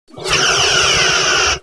otherphaser2.ogg